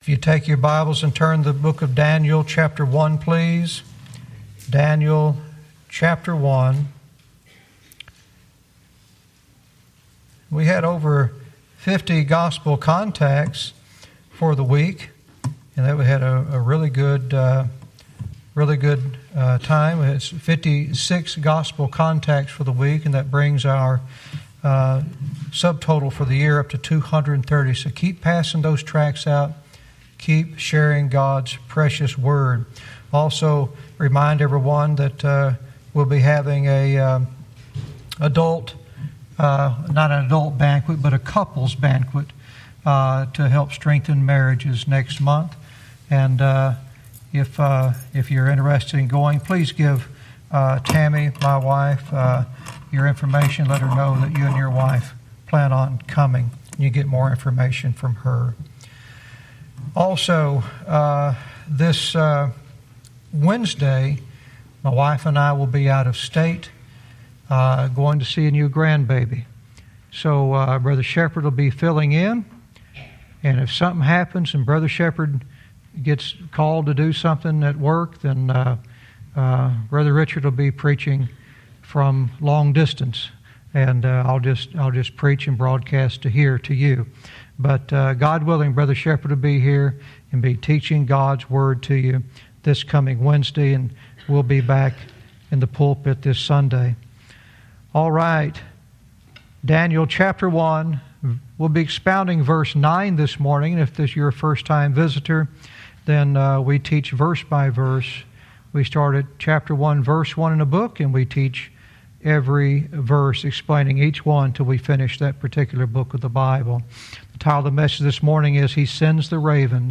Verse by verse teaching - Daniel 1:9 "He Sends the Ravens"